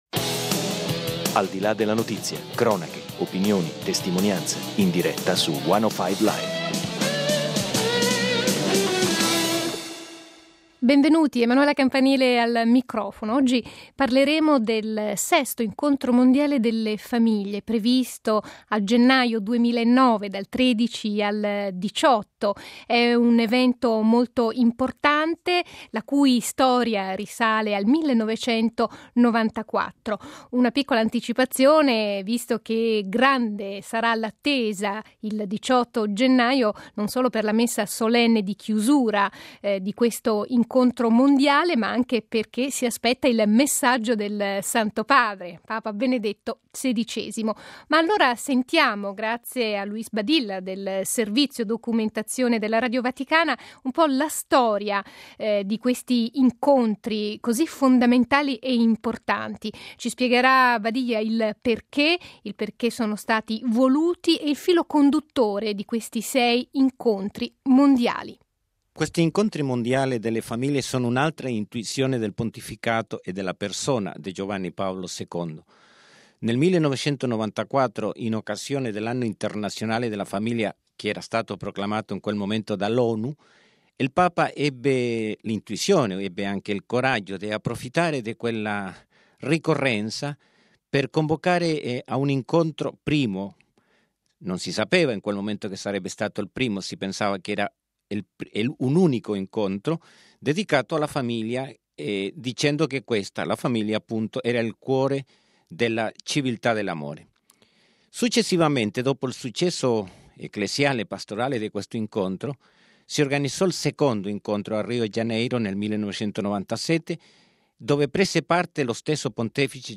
Nell'intervista al card. Ennio Antonelli, Presidente del Pontificio Consiglio per la Famiglia, i momenti salienti.